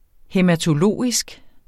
Udtale [ hεmatoˈloˀisg ]